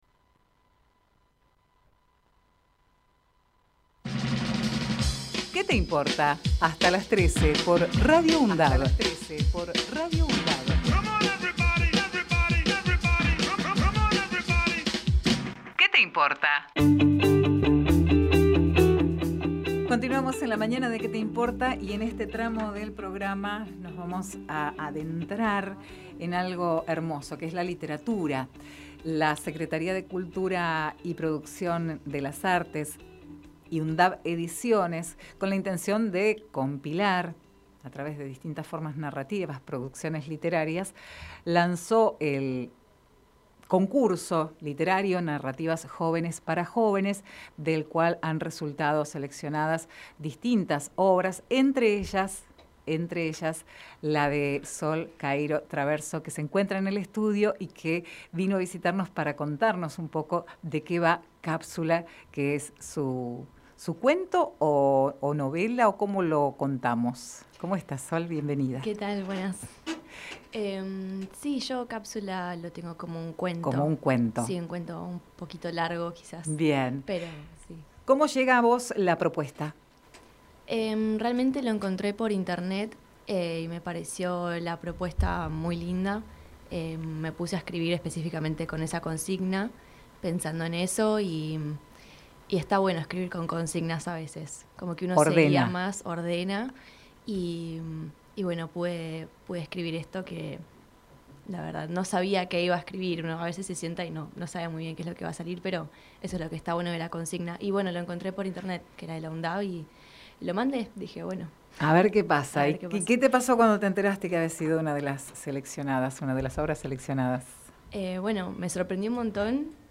Compartimos con ustedes la entrevista